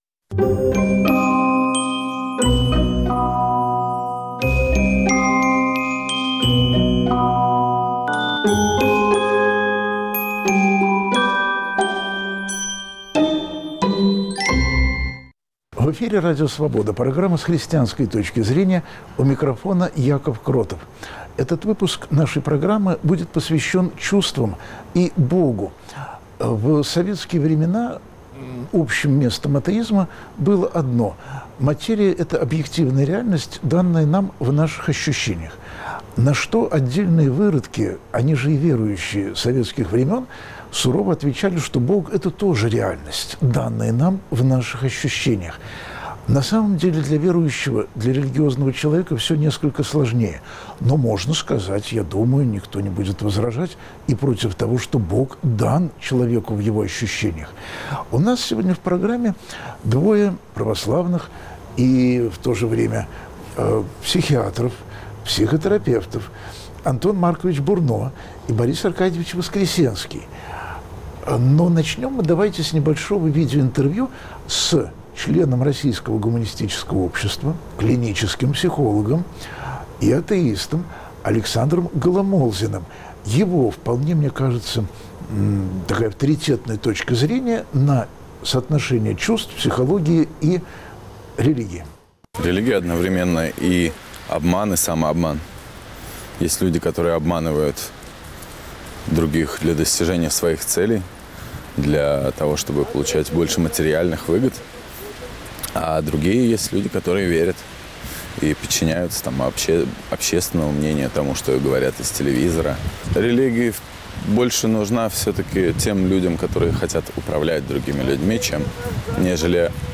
В программе, которую ведет священник Яков Кротов, слушают друг друга верующие и неверующие, чтобы христиане в России были не только большинством, но и работниками свободы Божьей и человеческой.